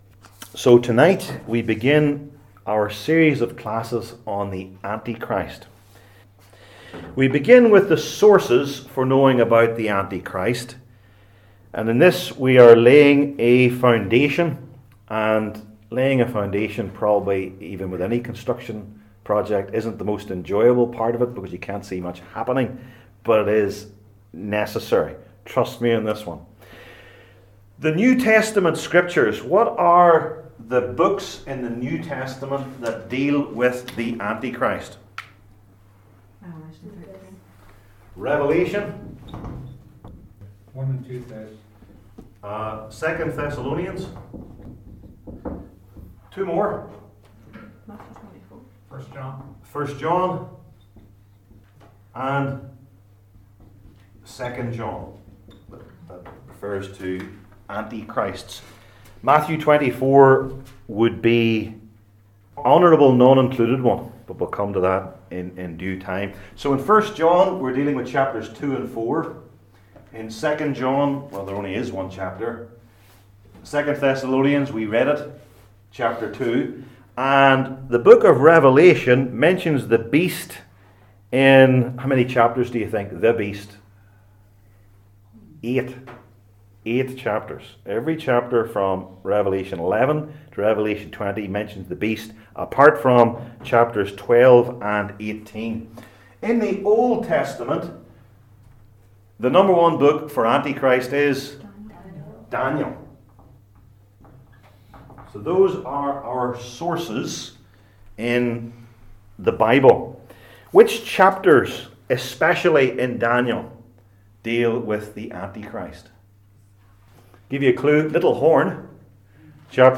Passage: II Thessalonians 2:1-15 Service Type: Belgic Confession Classes